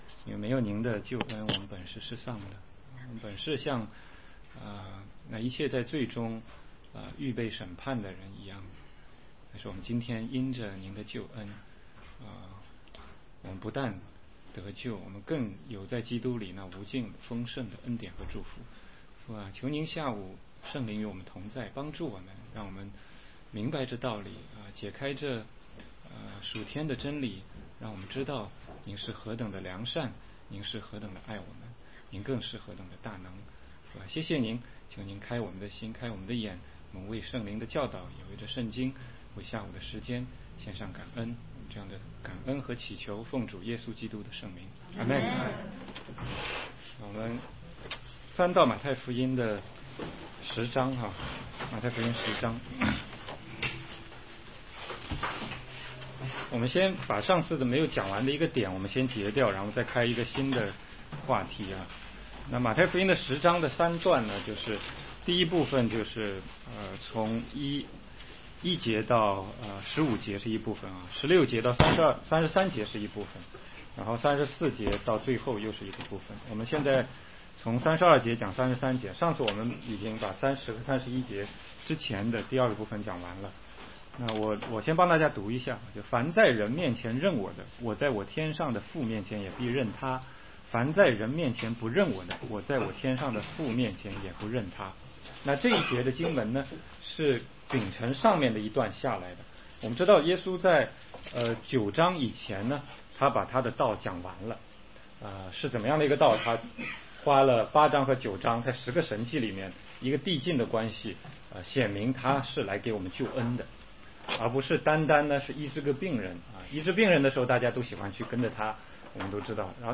16街讲道录音 - 马太福音